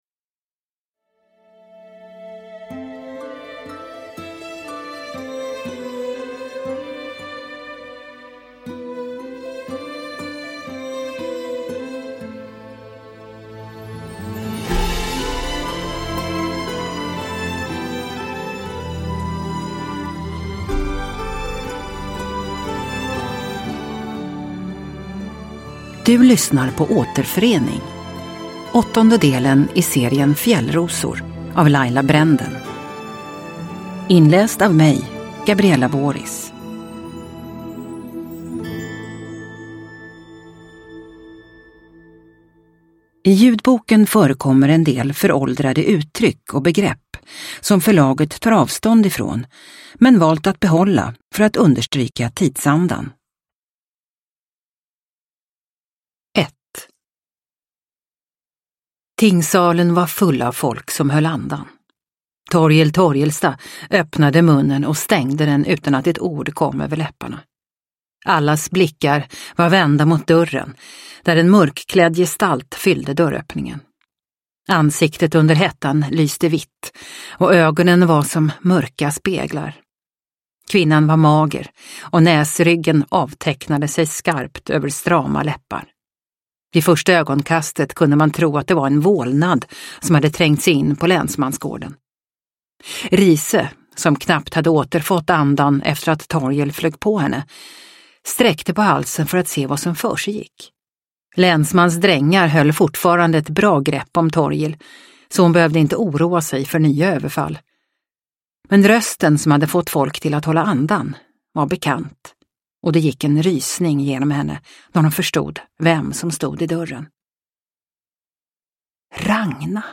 Återförening – Ljudbok – Laddas ner